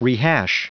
Prononciation du mot rehash en anglais (fichier audio)
Prononciation du mot : rehash